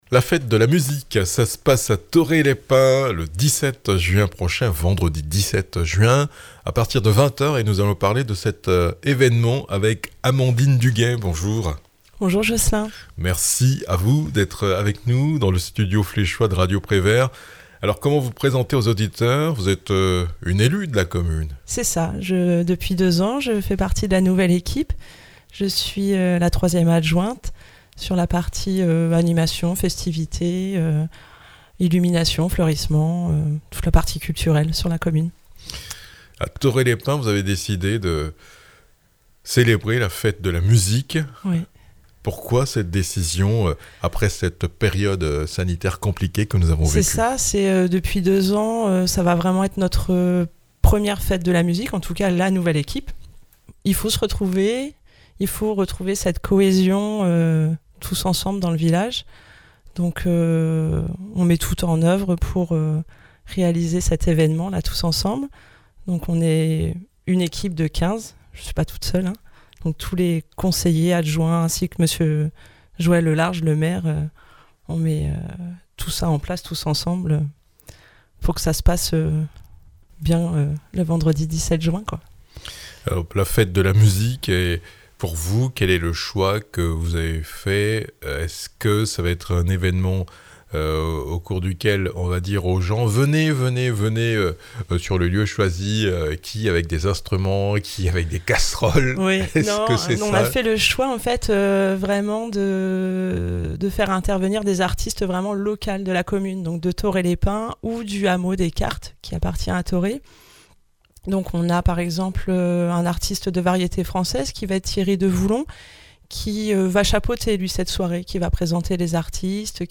A l'occasion de la Fête de la musique, la commune de Thorée-les-Pins organise une soirée le vendredi 17 juin 2022 à partir de 20h sur la place de l'église. Amandine Duguet, adjointe au maire en charge de l'animation et des festivités, présente le programme de l'événement qui inclut prestations d'artistes locaux, stand gâteaux crêpes cafés, restauration sur place en collaboration avec le restaurant La Pomme de Pin (chipo/pomme de terre grenaille)...réservation possible.